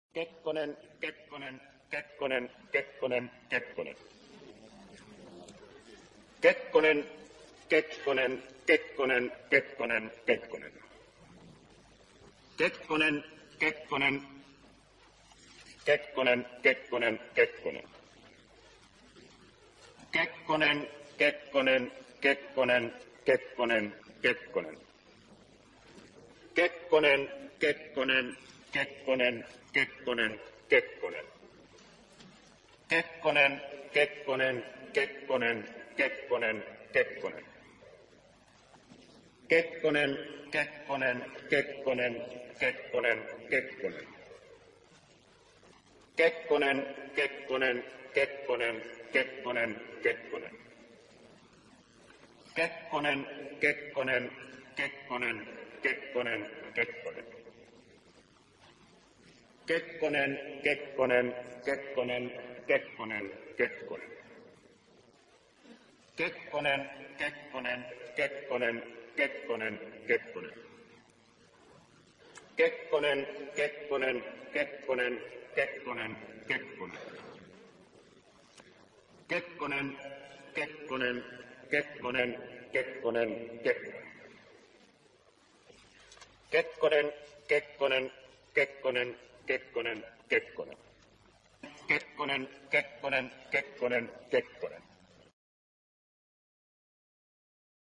这是一个为竞选德克萨斯州州长的候选人进行的民主筹款活动。